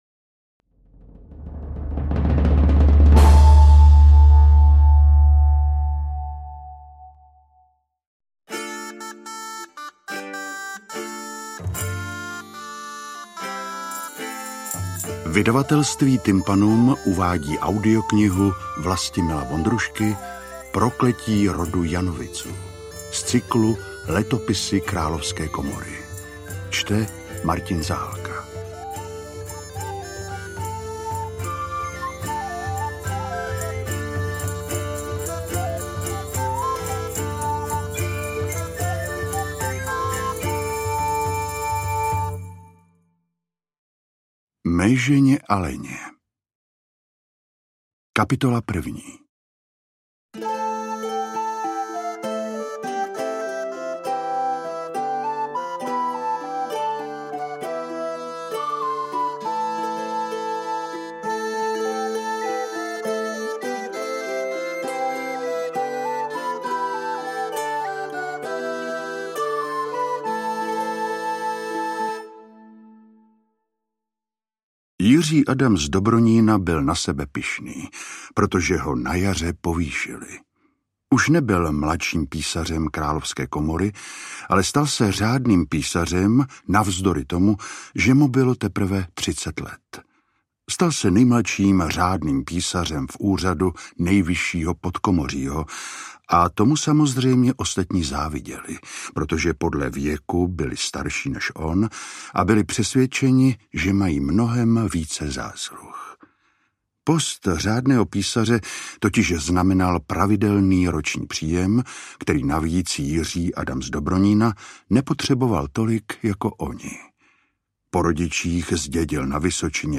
Interpret:  Martin Zahálka